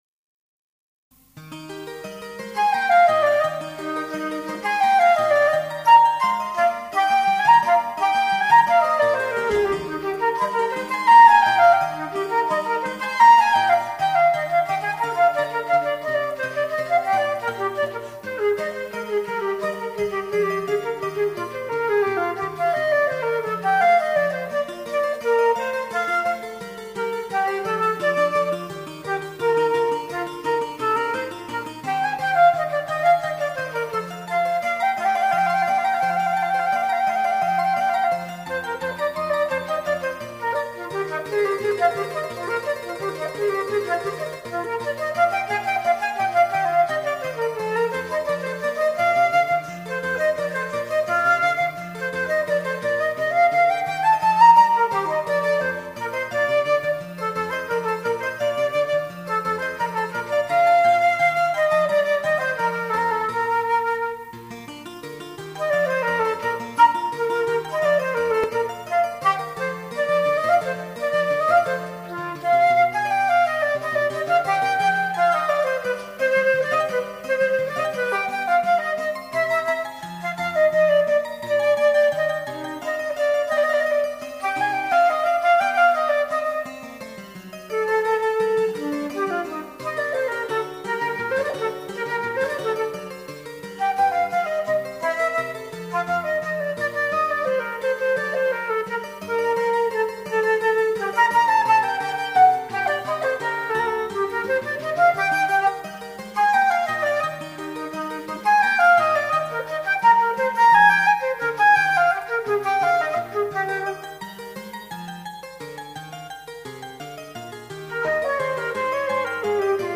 昔公開した私の演奏です。
Flute Sonata Es dur BWV1031 II (J. S. Bach)